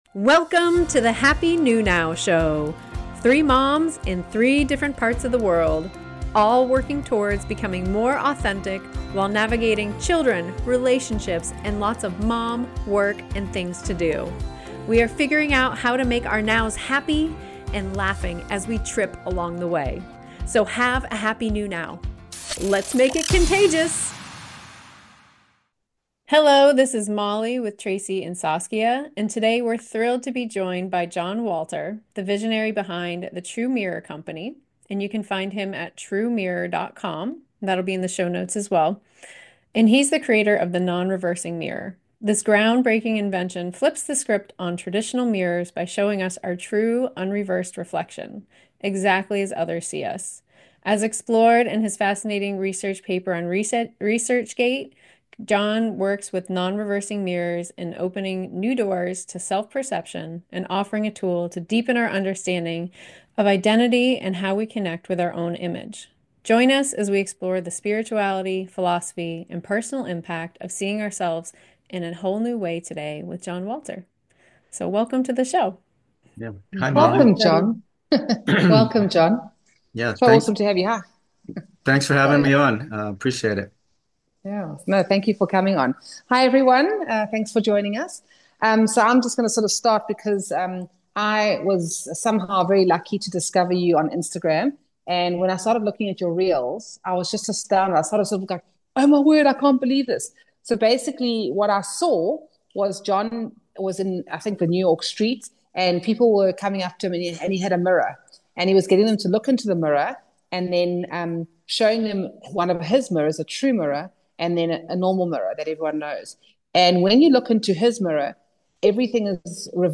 Talk Show
3 Moms, in 3 different parts of the world.